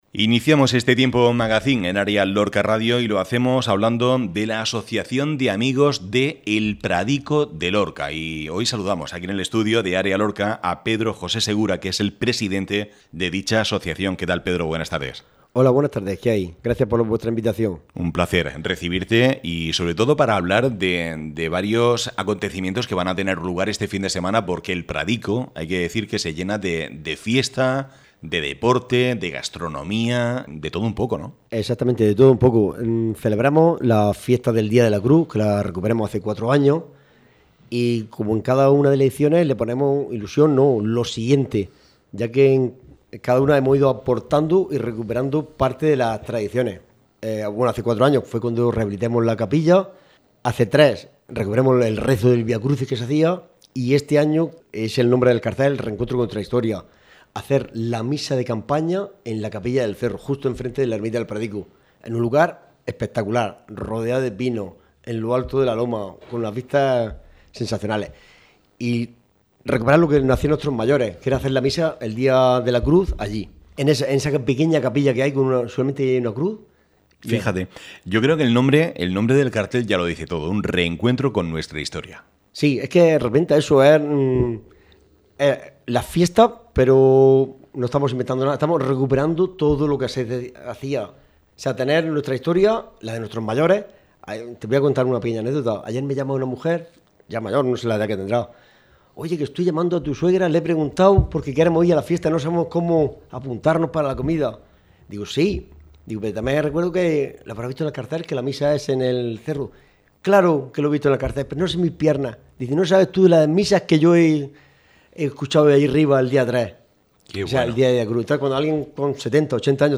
ÁREA LORCA RADIO. Actualidad.